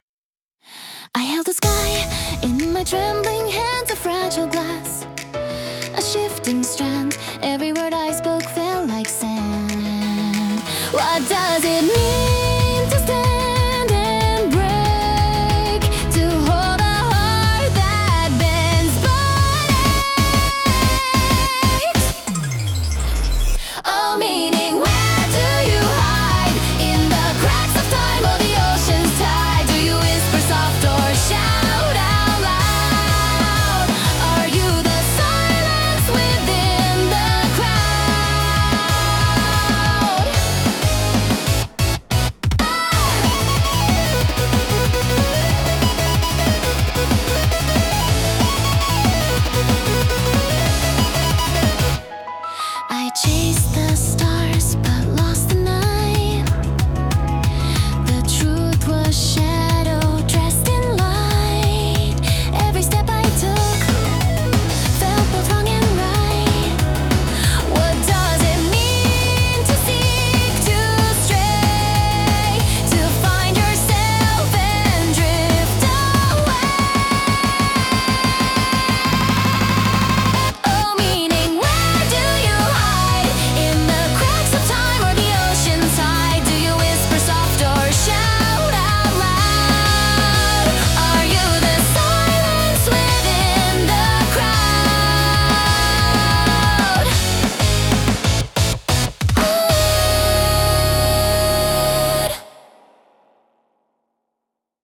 アニメ音楽は、日本のアニメ主題歌をイメージしたジャンルで、ポップでキャッチーなメロディとドラマチックな展開が特徴です。
明るくエネルギッシュな曲調から感動的なバラードまで幅広く、視聴者の感情を引き立てる要素が豊富に詰まっています。